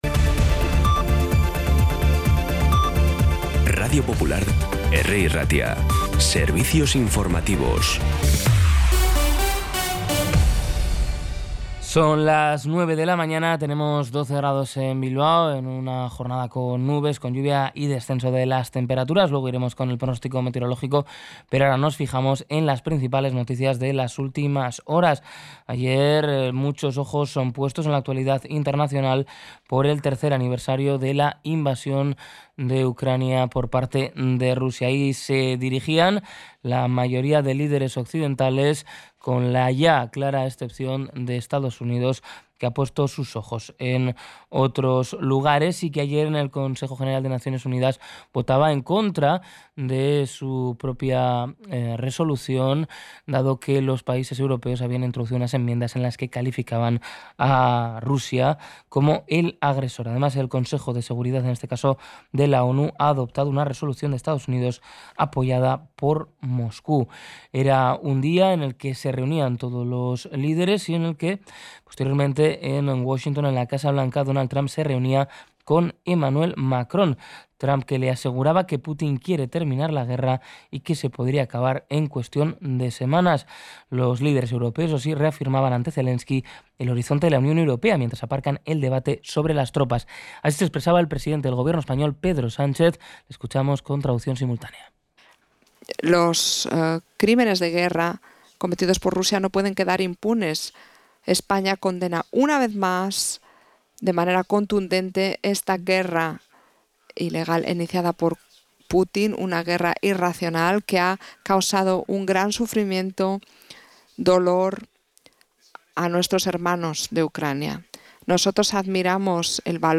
Las noticias de Bilbao y Bizkaia del 25 de febrero a las 9
Los titulares actualizados con las voces del día. Bilbao, Bizkaia, comarcas, política, sociedad, cultura, sucesos, información de servicio público.